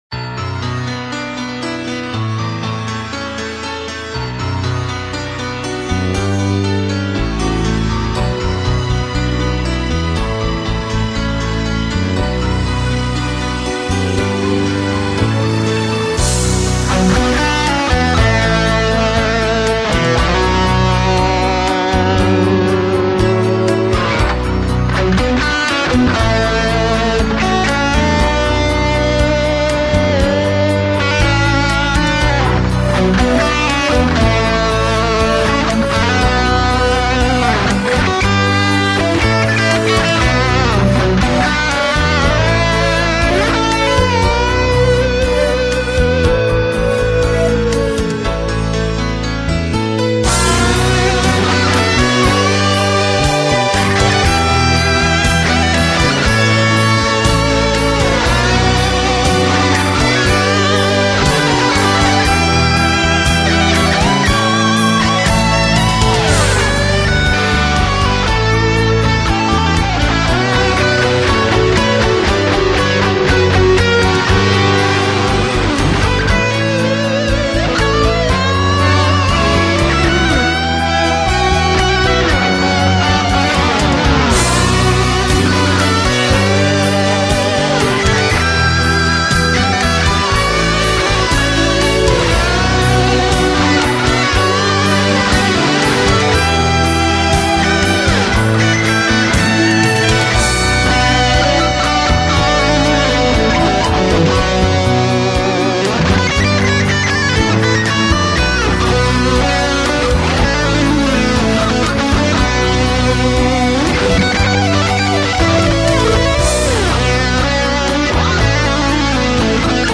Vos Compos Rock
J'aimerais avoir vos avis sur une compo instrumentale faite il y a plus d'une dizaine d'années.
Ca a été enregistré sur un Tascam 8 pistes à K7.
Les guitares sont là un peu comme des explications entre deux personnes et se répondent...
La batterie pas assez en avant... Ca fait un peu timide au niveau du son, même la guitare... mais étant donné le matos etc !
Vraiment cool, j'adore les trucs qui sonnent hard rock old school, seul reproche : les claviers et pianos sonnent vraiment ringards aujourd'hui!